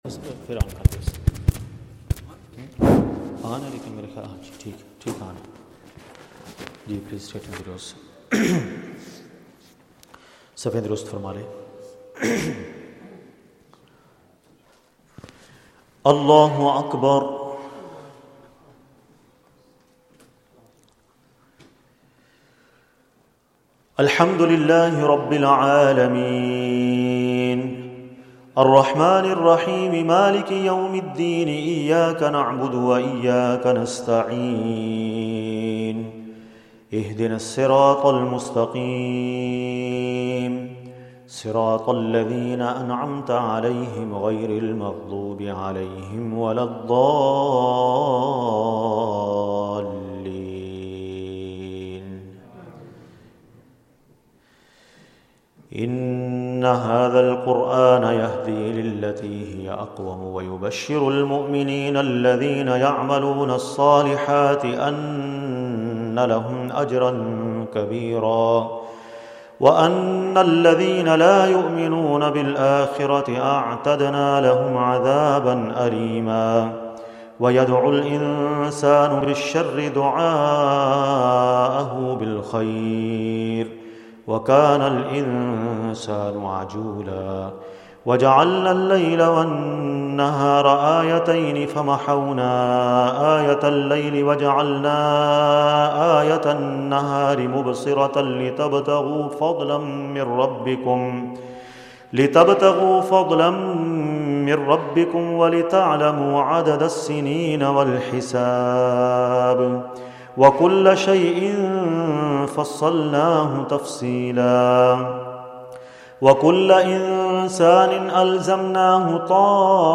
Zakariyya Masjid Motherwell | Taraweeh | eMasjid Live
Taraweeh